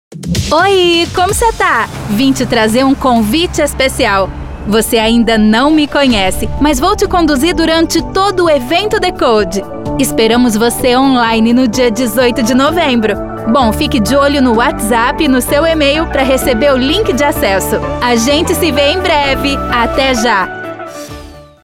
Feminino
Tenho voz jovem, natural, facilidade para interpretação, agilidade na entrega do trabalho e bons equipamentos.